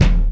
MB Kick (38).WAV